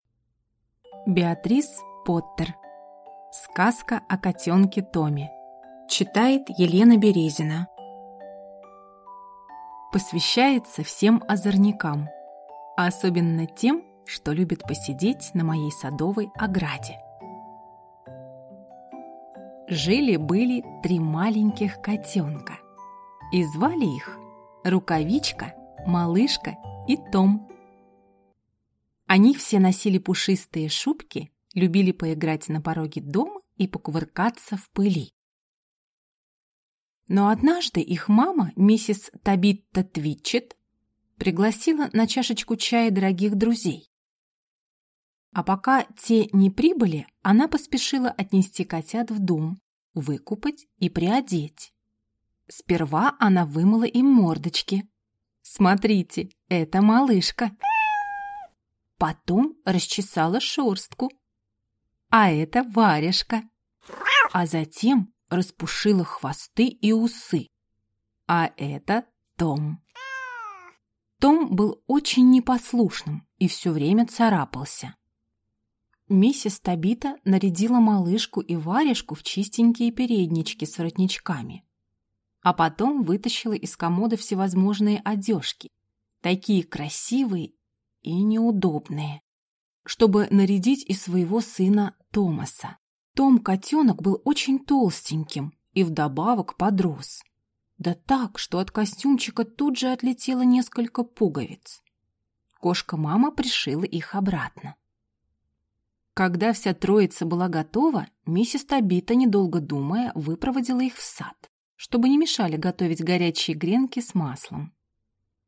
Аудиокнига Сказка о котенке Томе | Библиотека аудиокниг